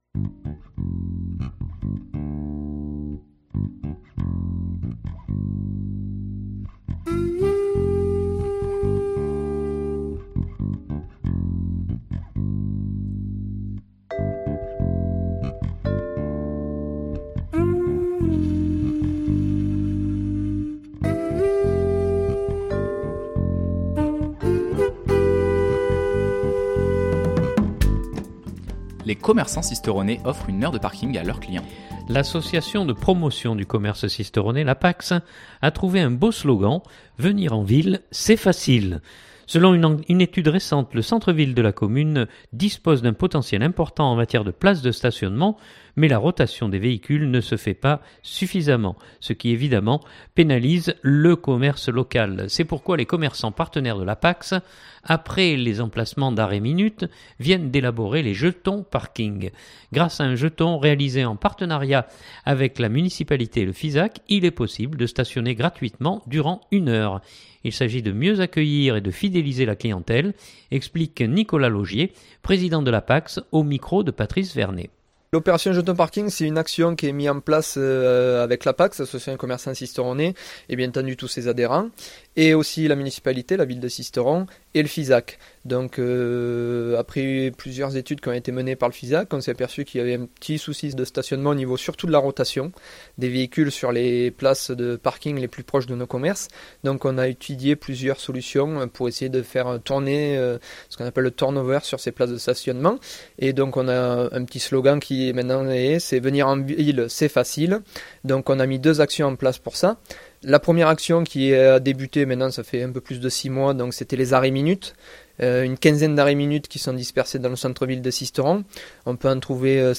ECOUTER Durée : 6' 45" Journal du 2016-11-09 Parking.mp3 (3.8 Mo)